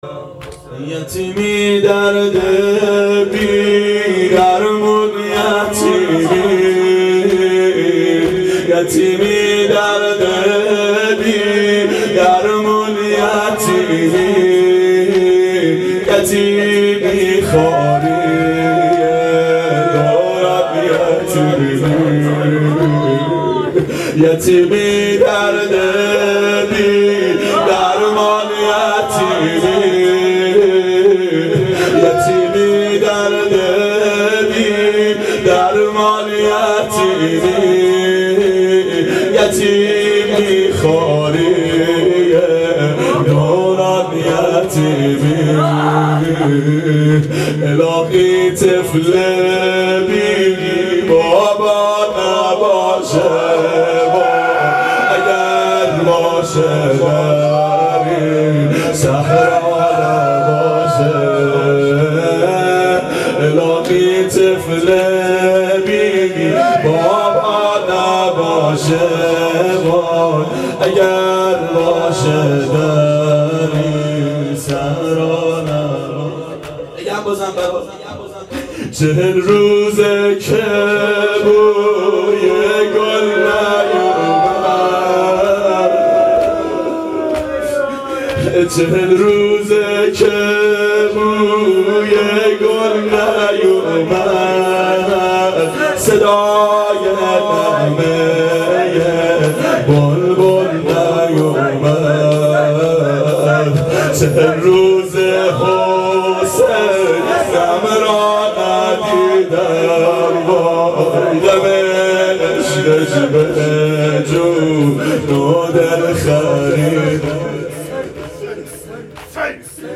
شب اربعین 92